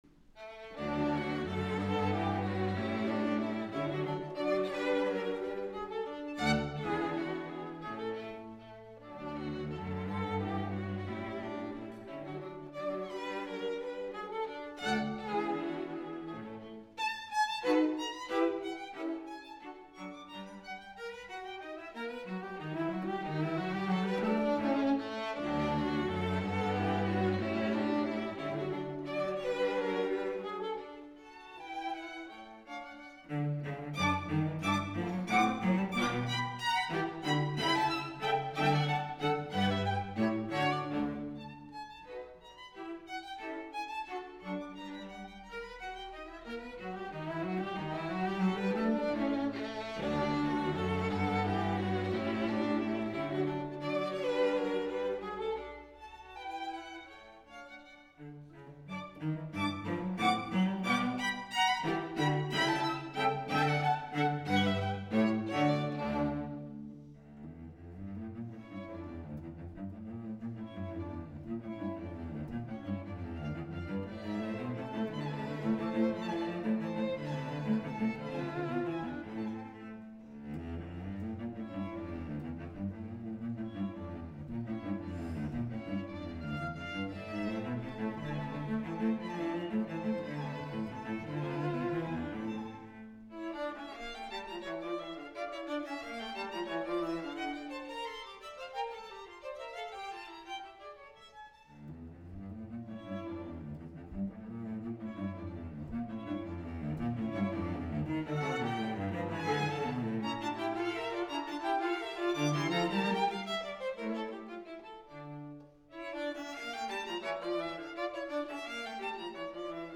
Listen to historic chamber music recordings online as heard at Vermont's Marlboro Music Festival, classical music's most coveted retreat since 1951.
String Quartet in D Major, Op. 76, No. 5, Hob. III:79